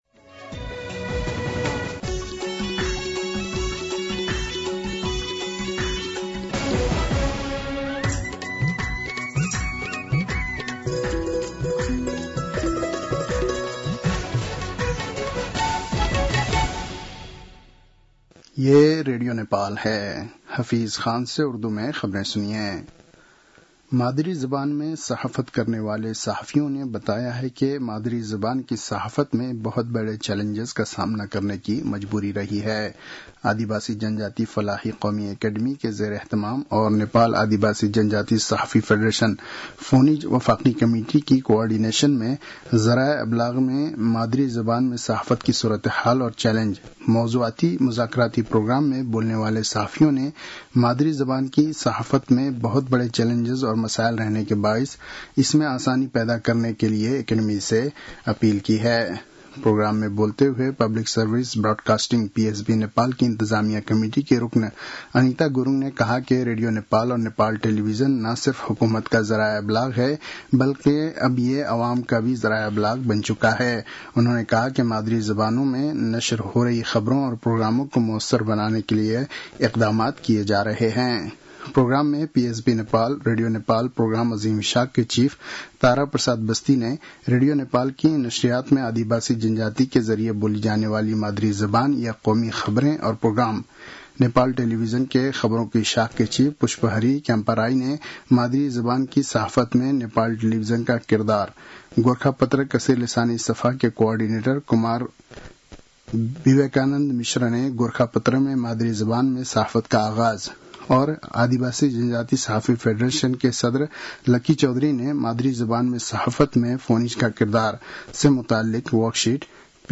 उर्दु भाषामा समाचार : १३ असार , २०८२